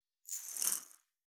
351岩塩を振る,調味料,カシャカシャ,ピンク岩塩,
効果音厨房/台所/レストラン/kitchen